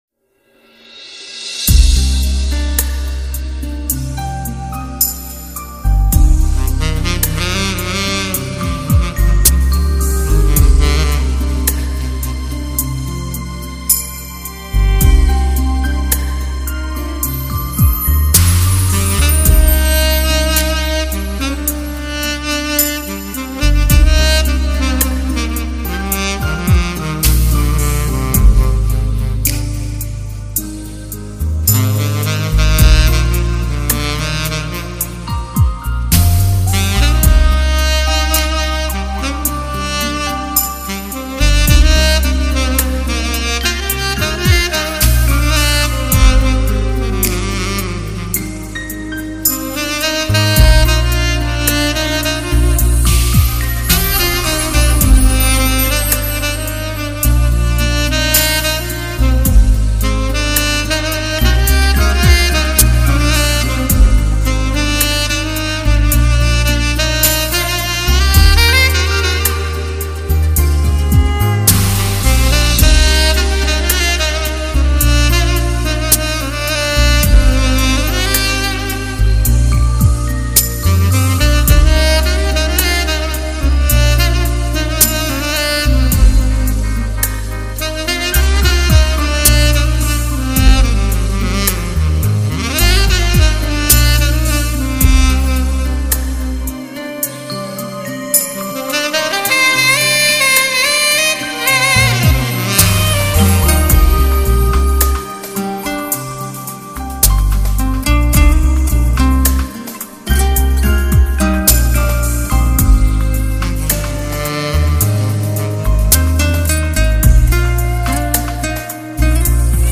心醉情迷的音乐 如火似电般热烈  挑动人心的缠绵